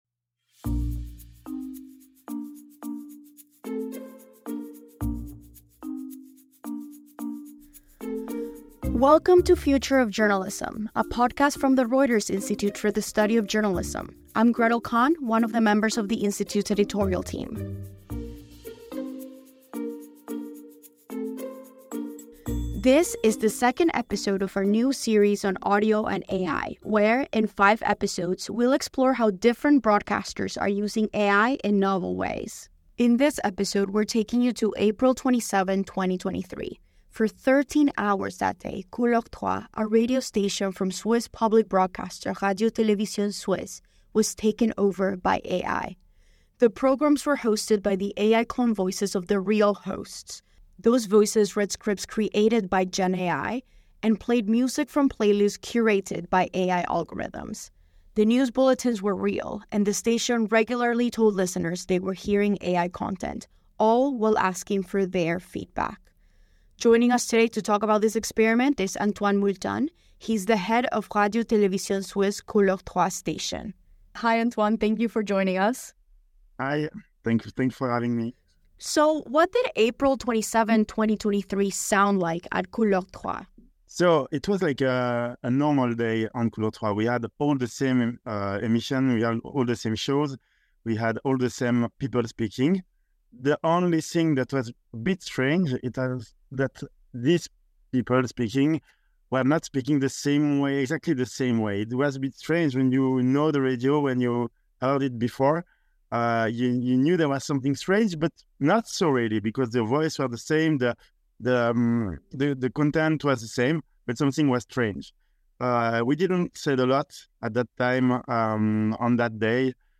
People